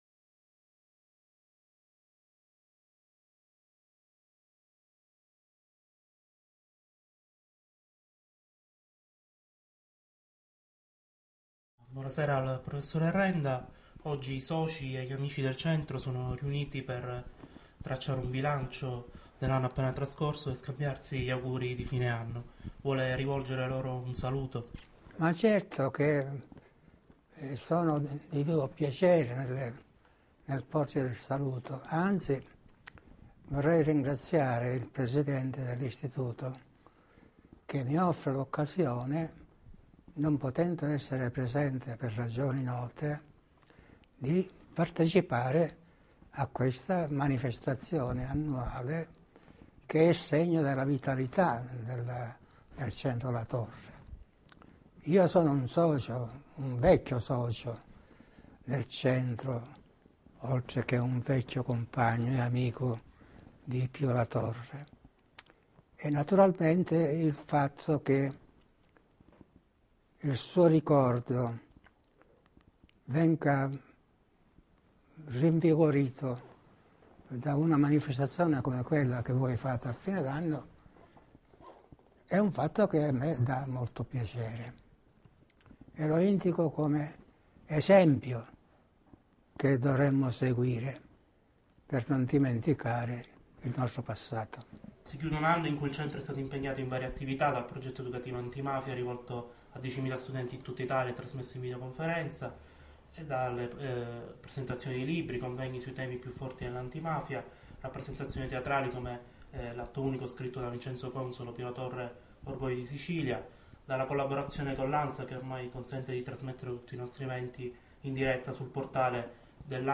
Intervista a Francesco Renda
Prof. Francesco Renda storico